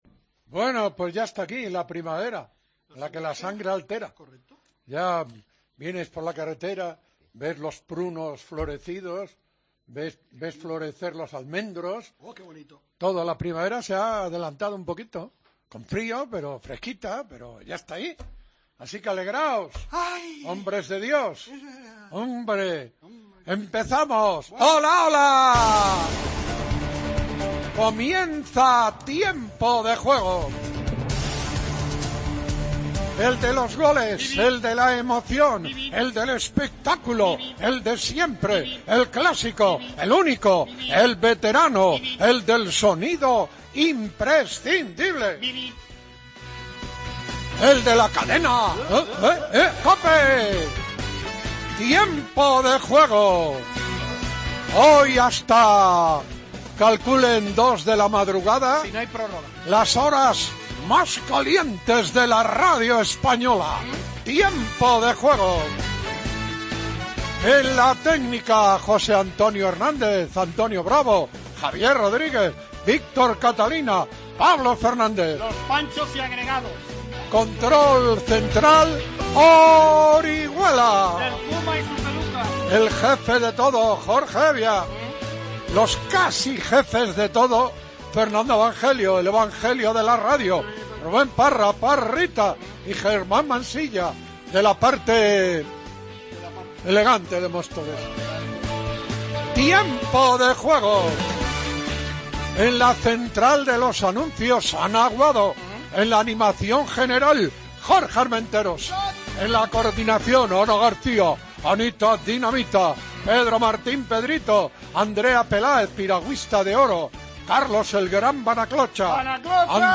Previa del Barcelona-Getafe, partido de la 29ª jornada de la Liga BBVA. Escuchamos lo mejor de la rueda de prensa de Zidane.